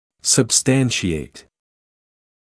[səb’stænʃɪeɪt][сэб’стэншиэйт]подкреплять (данными); обосновывать; наполнять содержанием